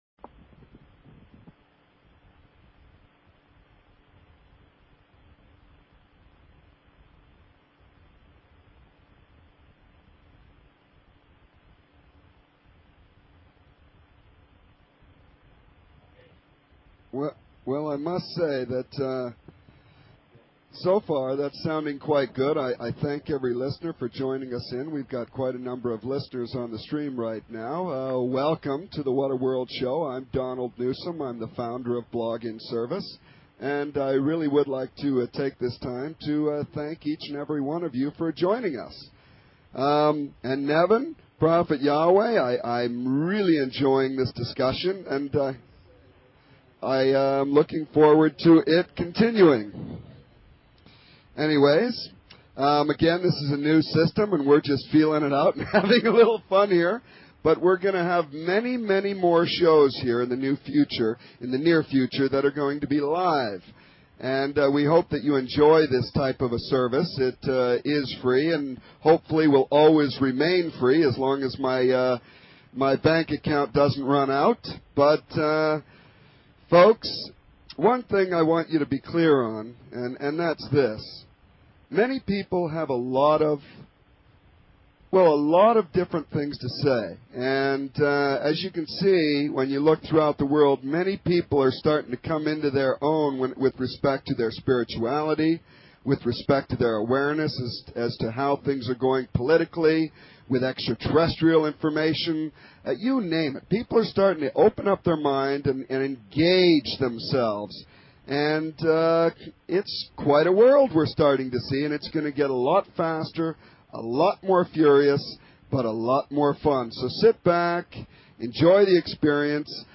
Talk Show Episode, Audio Podcast, What_A_World and Courtesy of BBS Radio on , show guests , about , categorized as